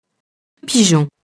pigeon [piʒɔ̃]